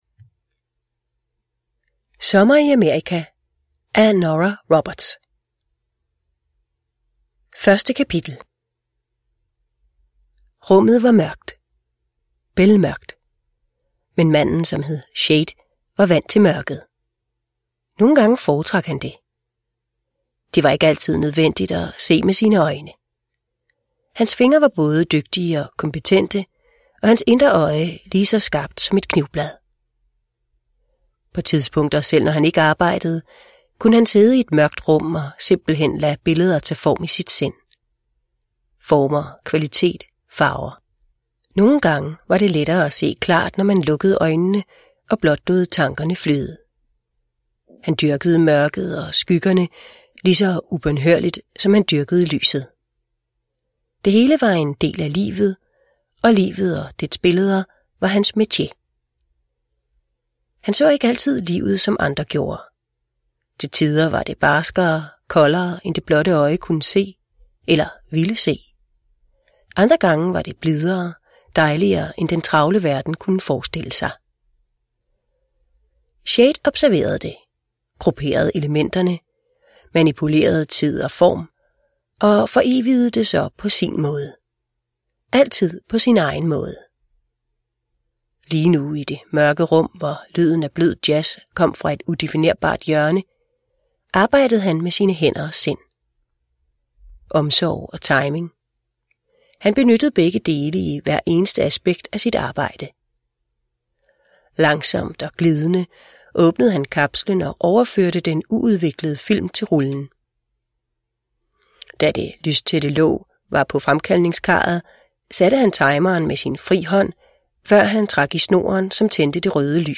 Sommer i Amerika – Ljudbok – Laddas ner